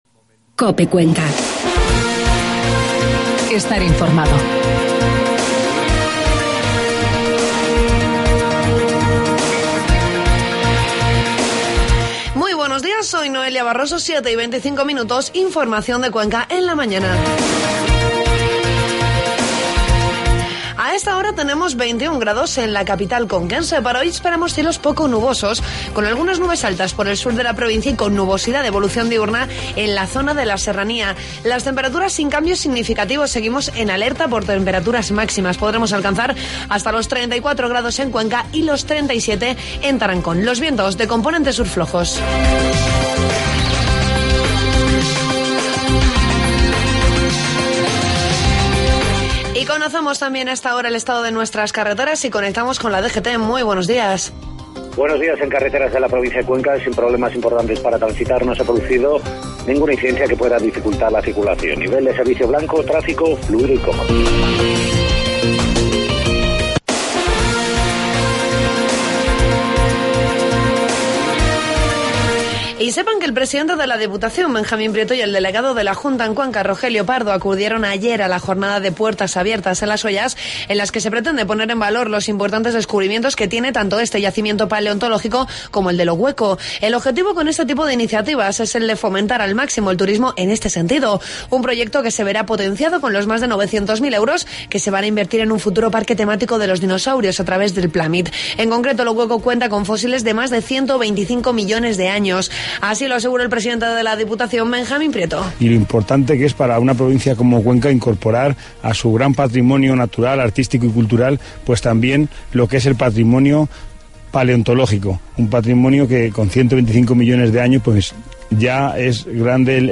Informativo matinal 12 de julio